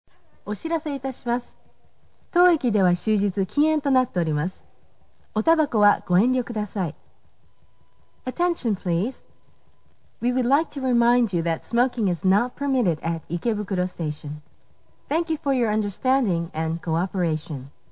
全ホーム共通 発車メロディー
禁煙放送   Passenger MD